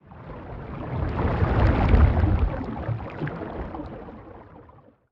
Sfx_creature_glowwhale_swim_slow_03.ogg